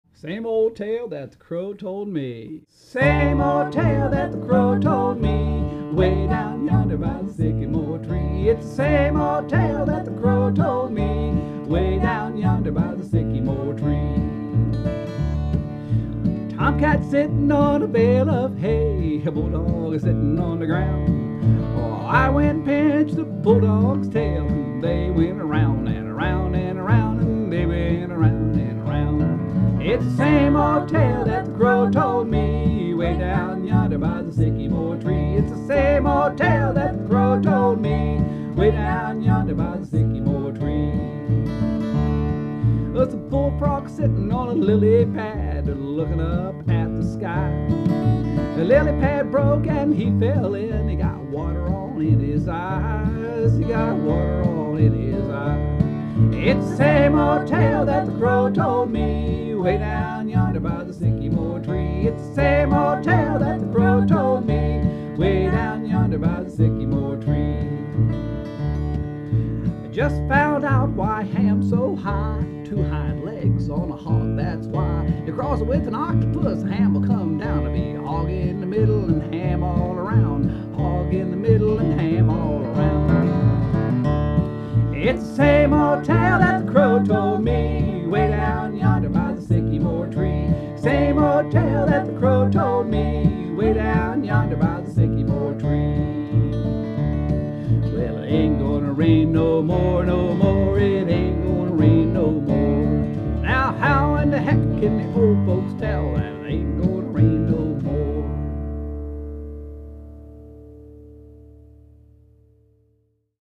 About Us and Mountain Music Stringed Instruments
Some songs for our Bluegrass for Children Program includes: